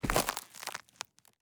PM_SDGS_14 Footstep Step Dry Grass Shrubs Pine Needles Meadow .wav